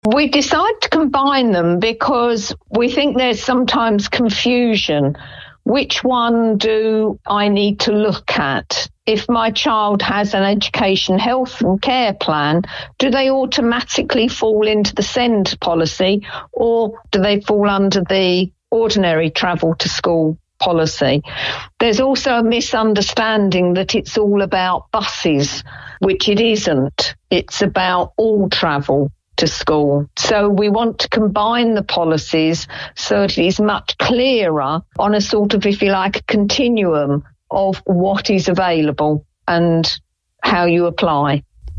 Councillor Christine Wise is Portfolio Holder for Transport, Environment and Communities and says they want to hear from parents, carers, schools, colleges and others who use a variety of school and SEND (special educational needs and/or disabilities) transport.